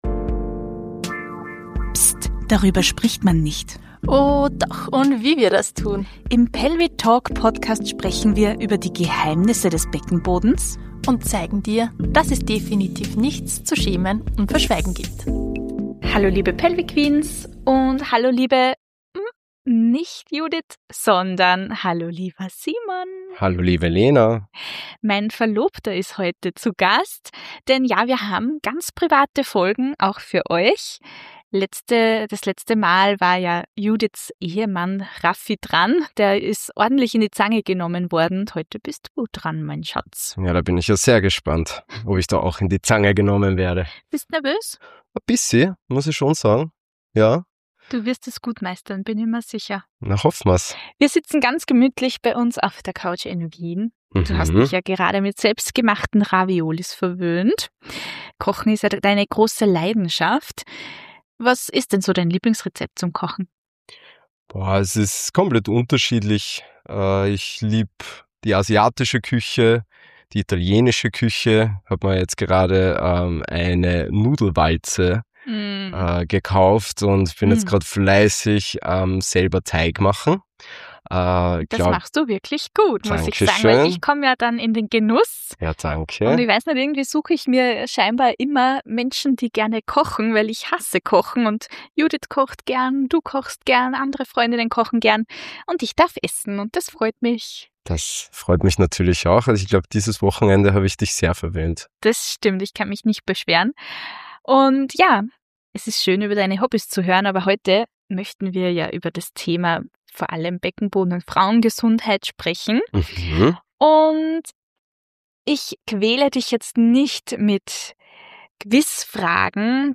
Ein ehrliches Gespräch